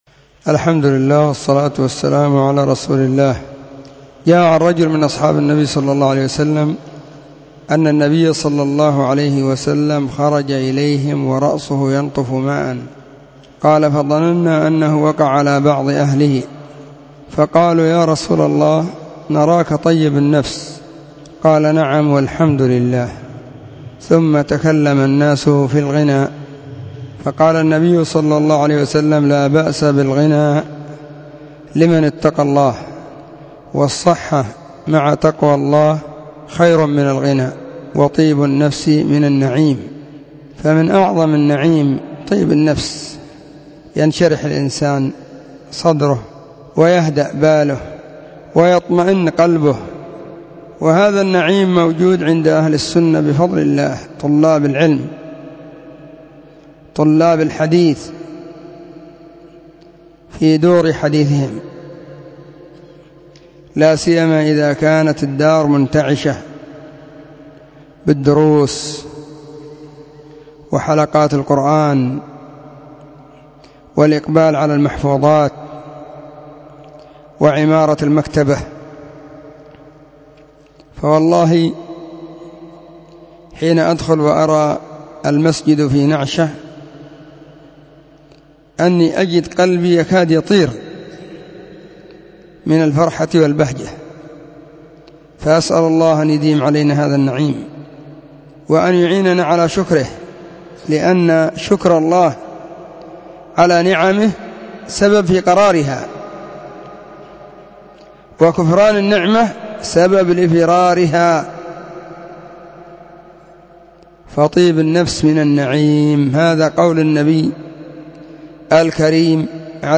🎙 كلمة قيمة بعنوان: *💿طيب النفس من النعيم💿*
📢 مسجد – الصحابة – بالغيضة – المهرة، اليمن حرسها الله.